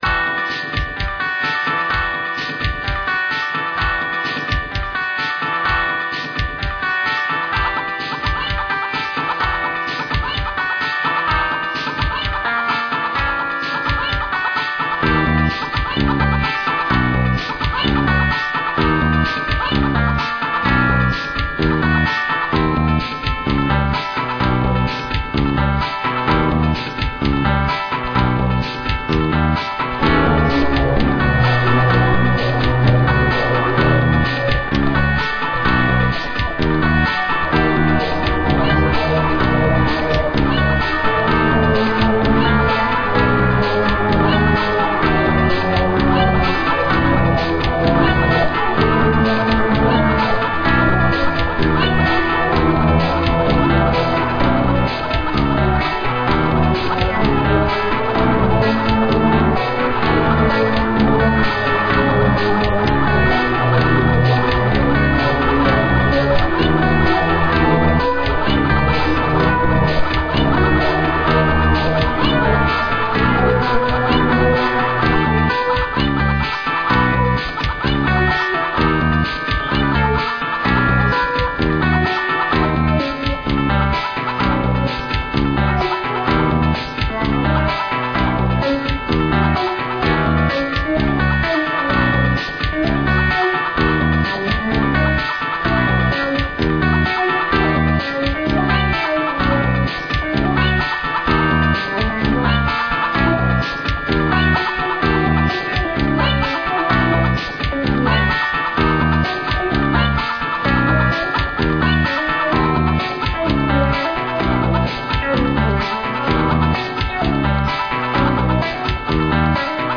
Upbeat pop guitar and keyboards.
Tags: pop